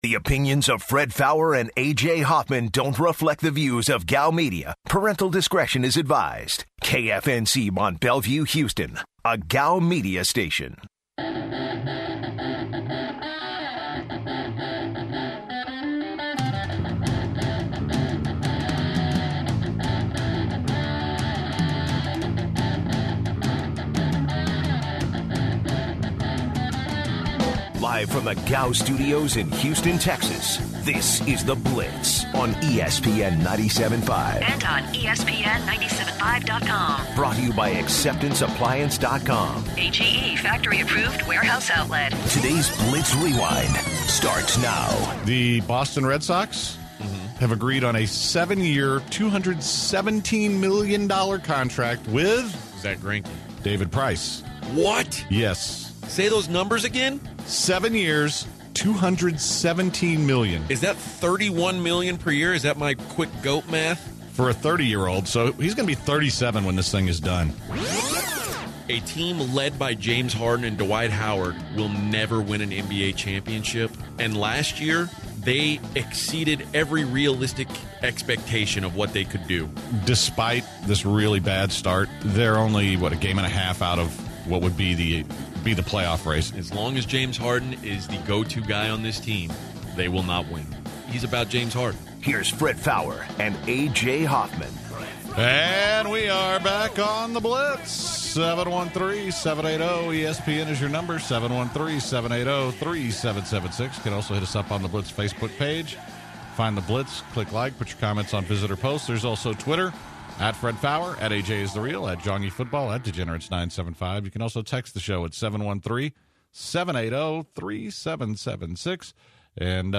To open the final hour, we tune in to the live feed of the CF committee selection. The guys, then, react to the committees selections.